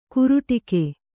♪ kuruṭi